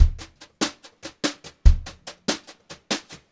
144GVBEAT1-R.wav